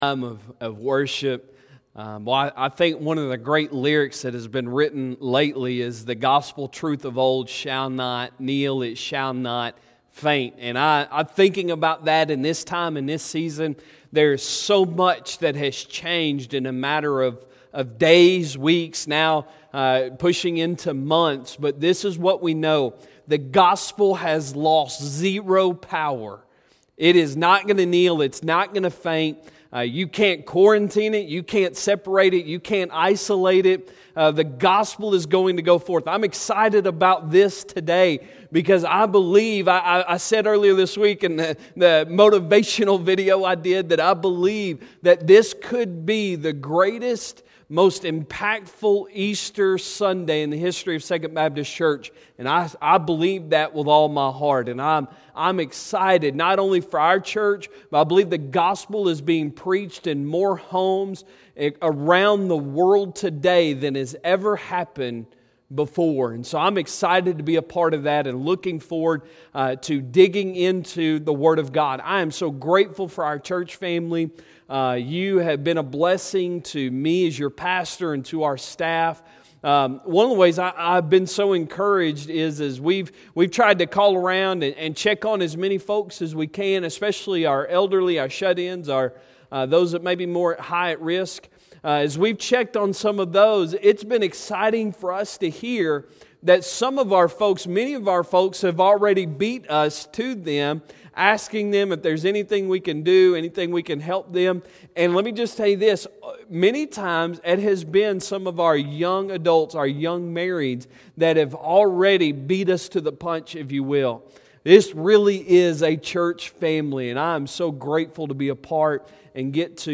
Easter Service April 12, 2020
Sermons